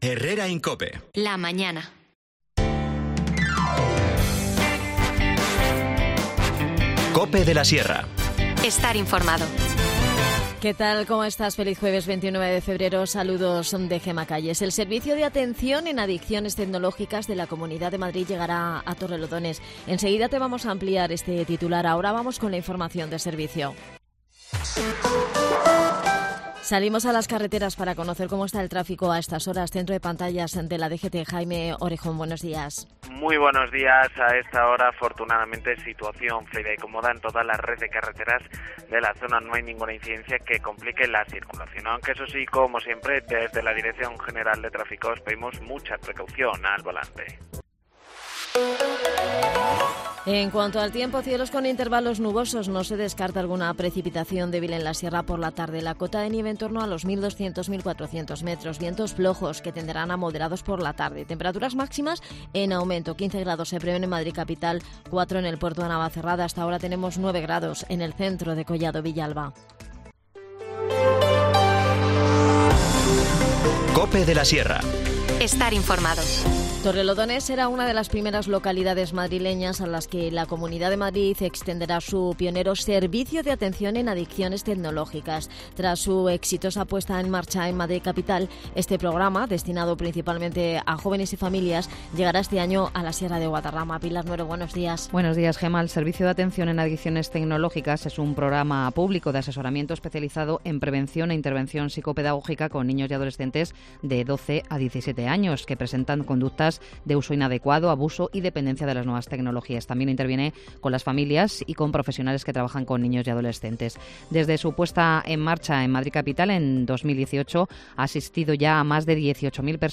Repasamos la actualidad de Collado Villalba, Capital de la Sierra con Adan Martínez, concejal de Comunicación que pasa por la inversión que ha hecho el Ayuntamiento en la mejora de los parques y jardines de la ciudad.